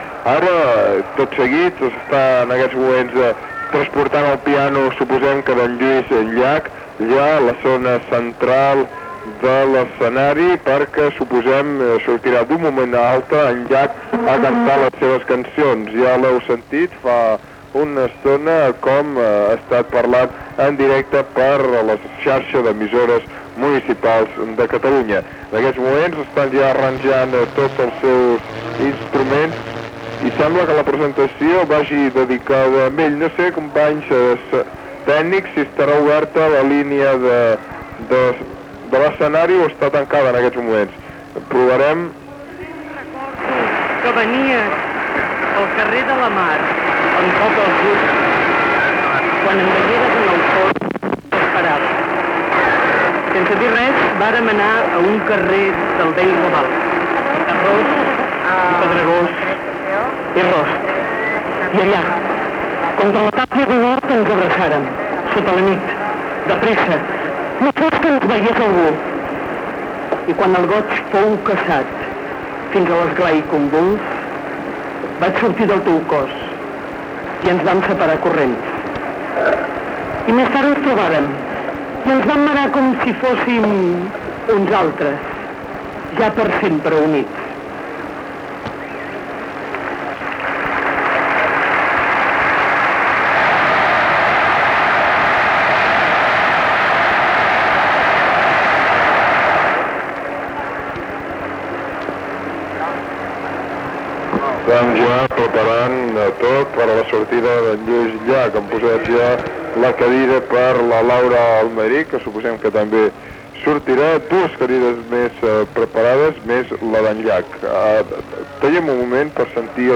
Transmissió d'EMUC del concert "Som una nació", al Camp Nou.
Informatiu